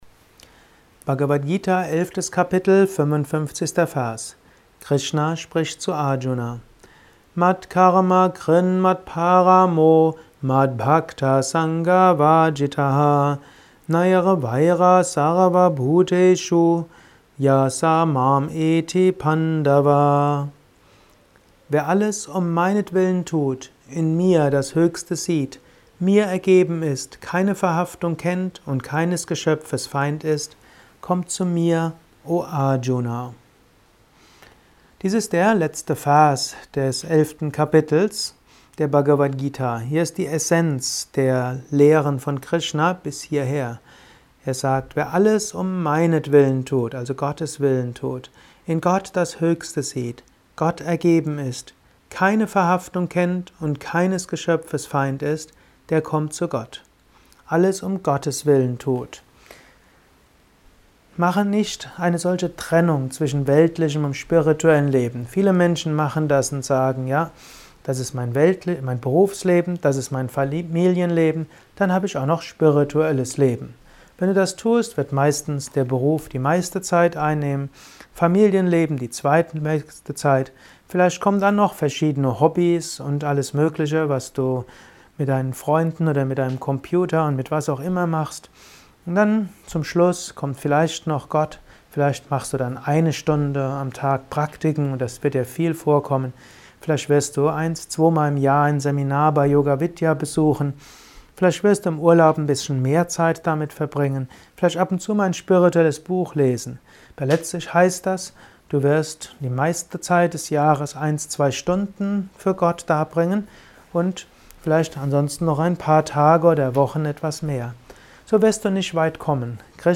Kurzvorträge
Dies ist ein kurzer Kommentar als Inspiration für den heutigen
Aufnahme speziell für diesen Podcast.